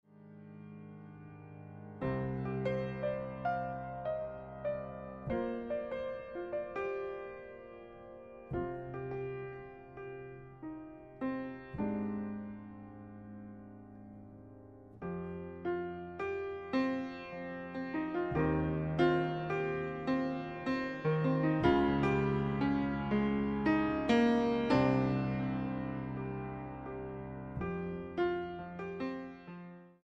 Keyboards, Computer
Piano Ballad, Elektro, Kinderlied,
Techno, Rock ...